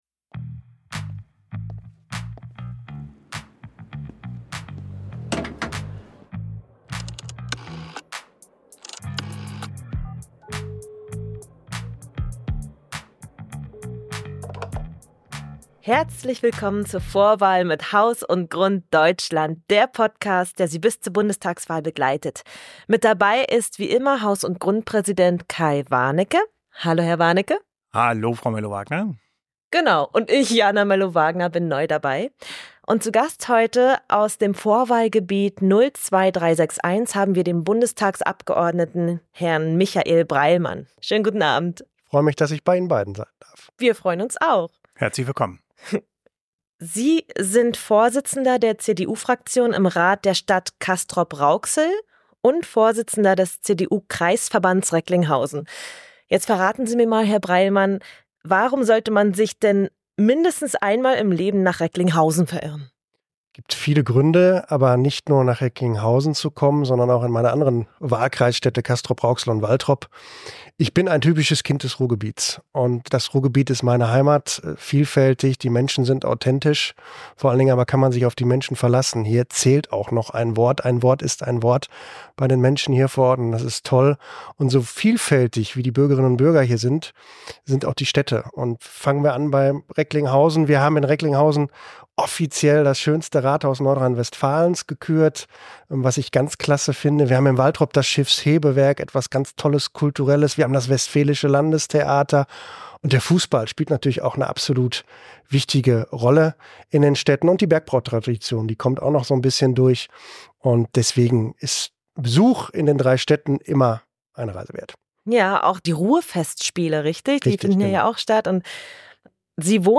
Bundestagsabgeordneter Michael Breilmann spricht mit uns in der ersten Folge „Vorwahl“ über das Wohnen, das Bauen, die kommunale Wärmeplanung und verrät, wie er das Ende der Ampel erlebt hat.